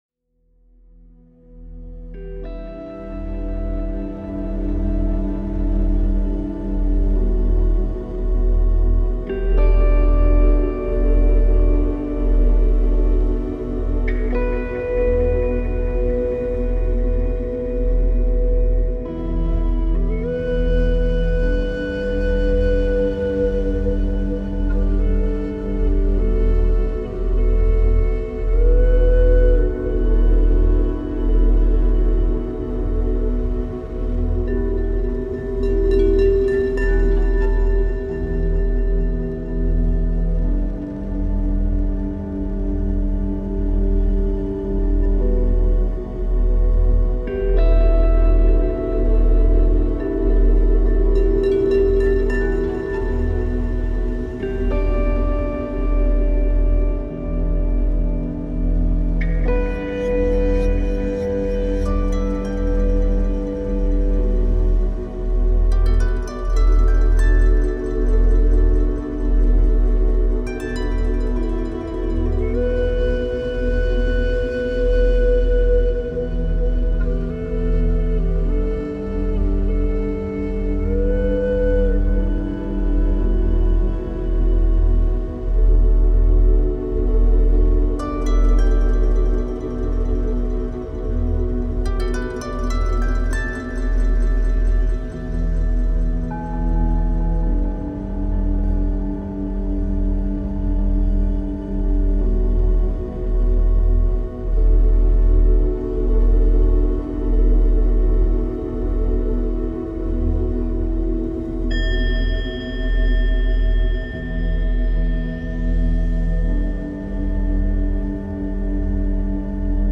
Cette flamme double 432 htz et 639htz active la guérison de l’âme
FRÉQUENCES VIBRATOIRES